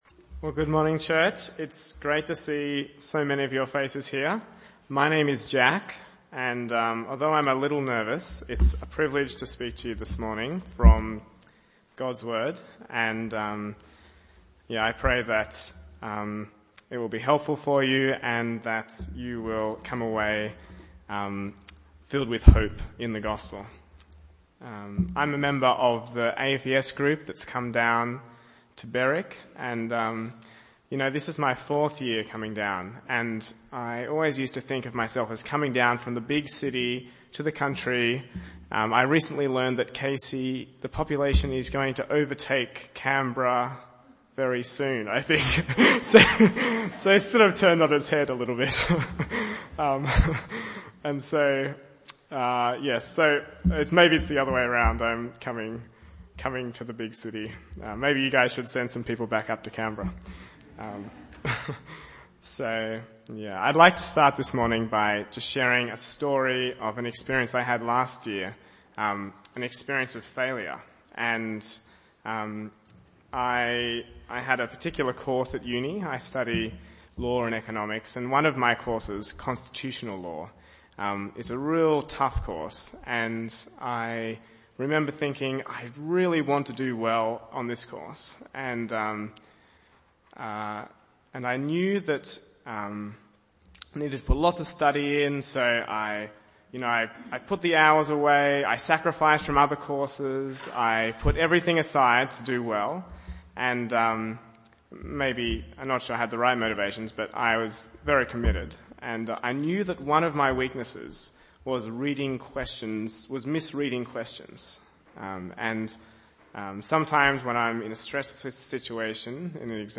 Bible Text: Luke 7:36-50 | Preacher